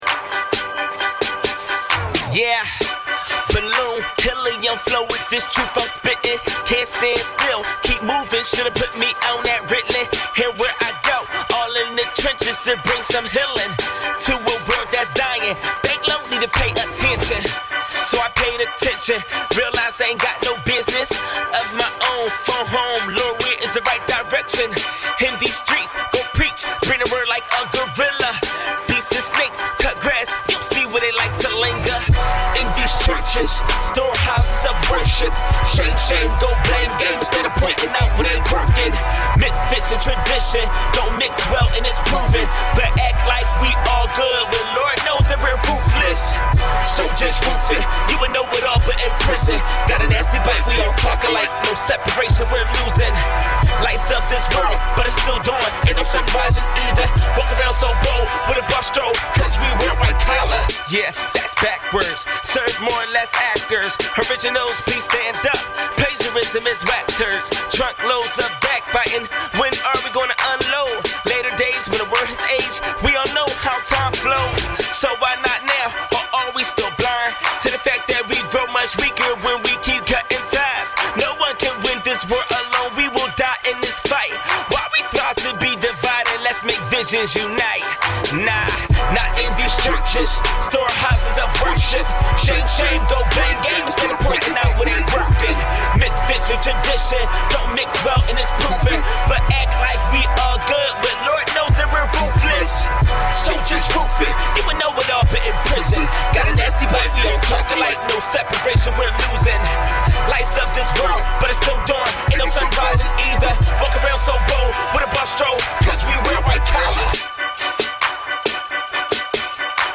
e foi produzido e divulgado de maneira independente.
hip hop